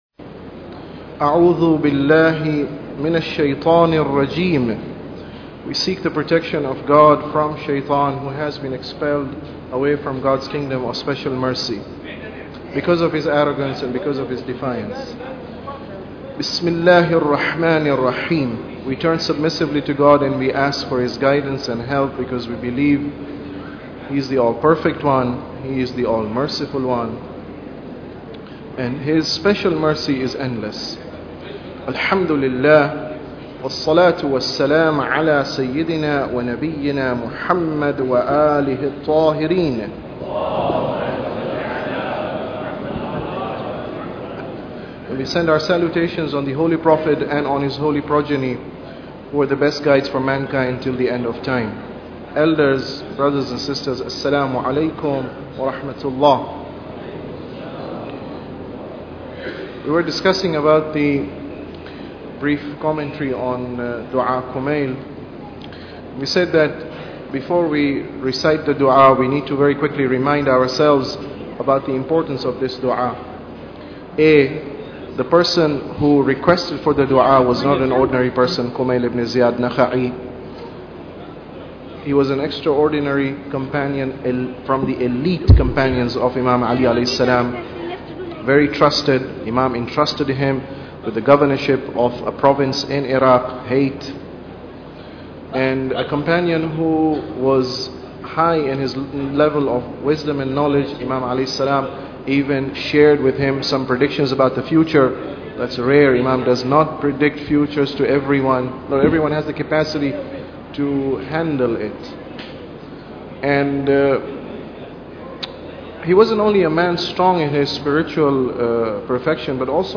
Tafsir Dua Kumail Lecture 5